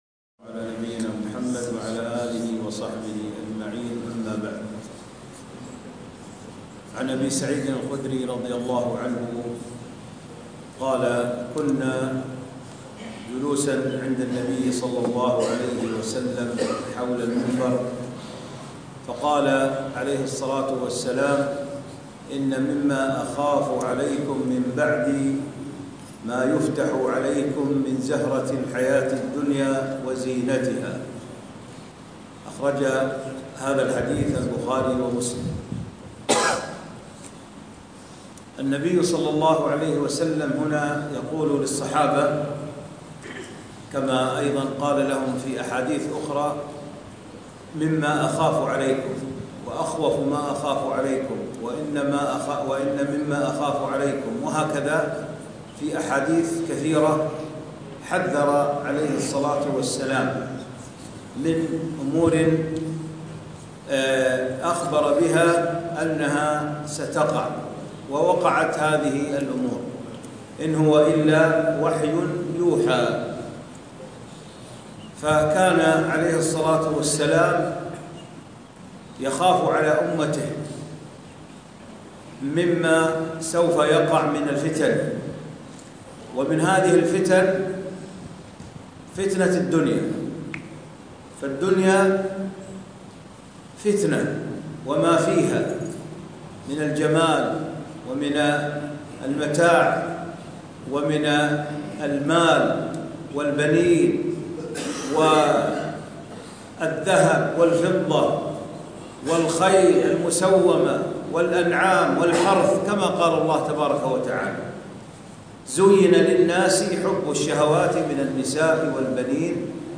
التحذير من هوى الدنيا - كلمة